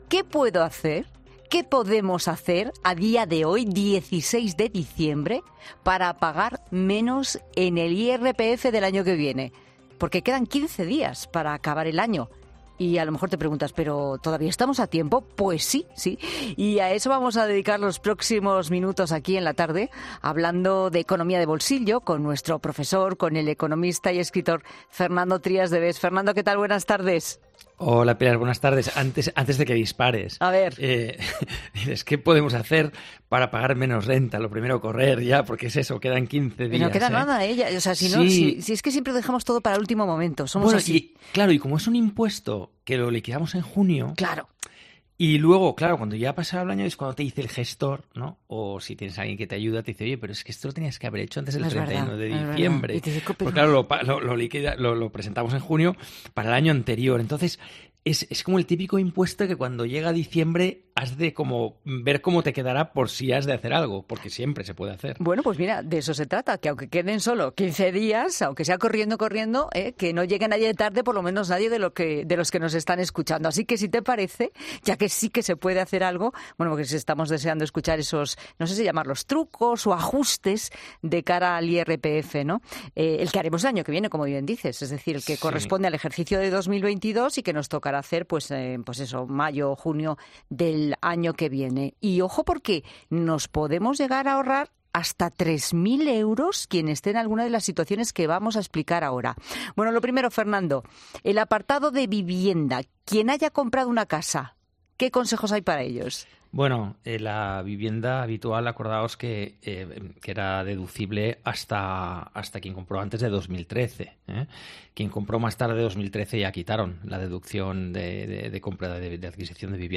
El economista Fernando Trías de Bes explica las tres vías para pagar menos en el IRPF de este año, siempre y cuando lo hagas antes de Año Nuevo